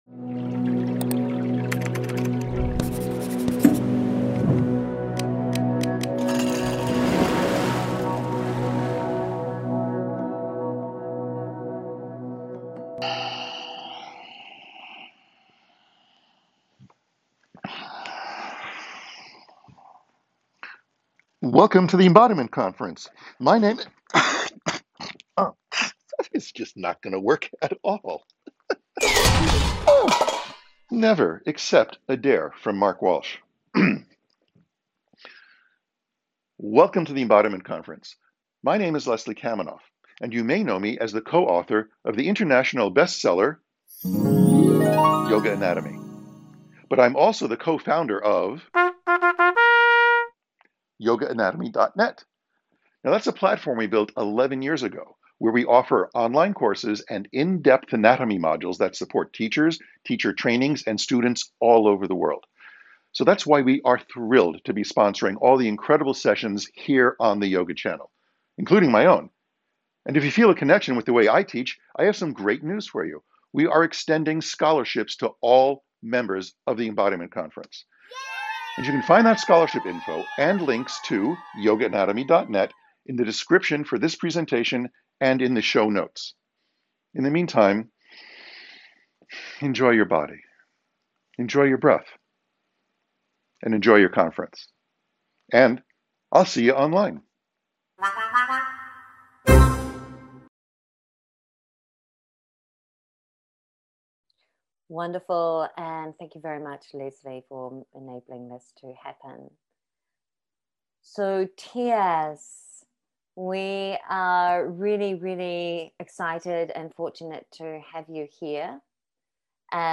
Yoga of the Subtle Body Intermediate understanding Some standing/ movement Likely soothing How can we open in the midst of a shut down?